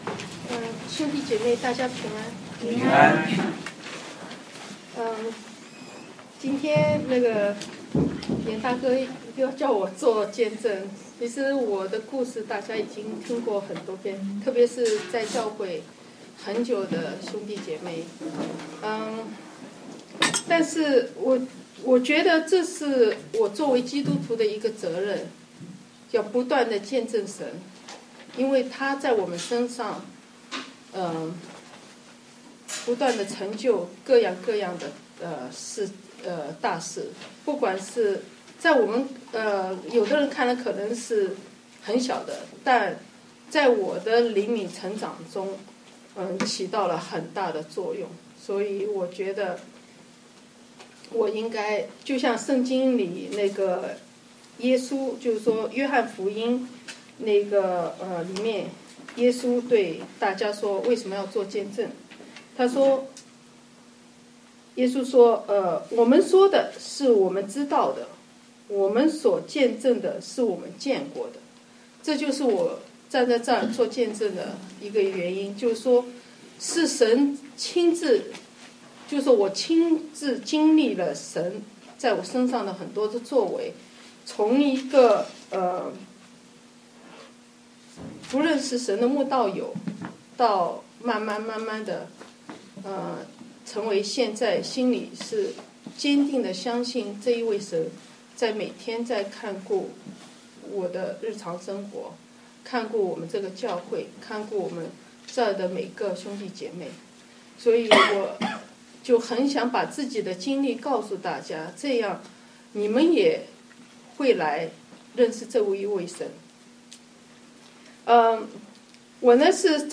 見證分享